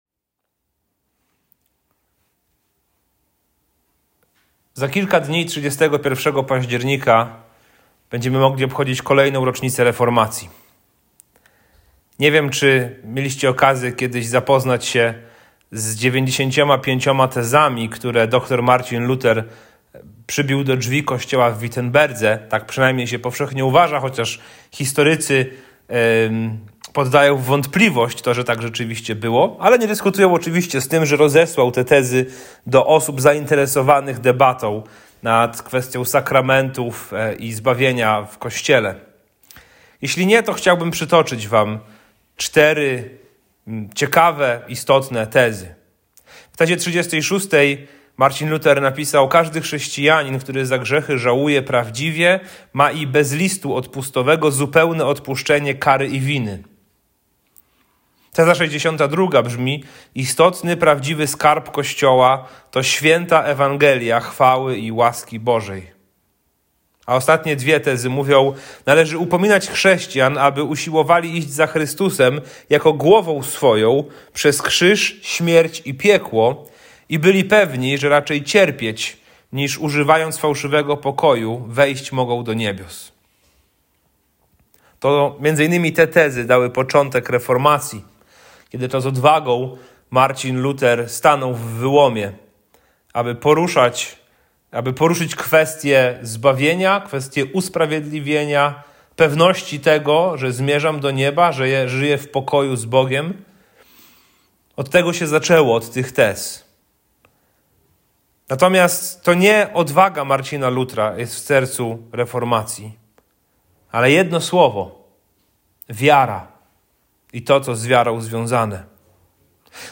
Kaznodzieja